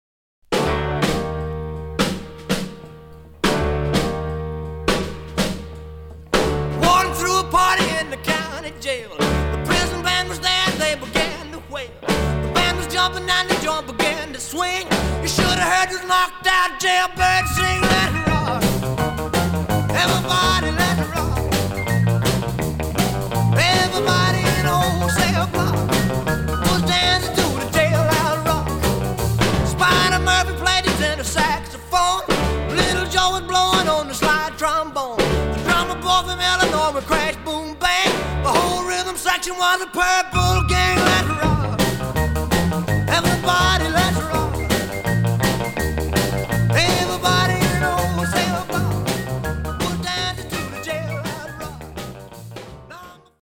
Mono-Material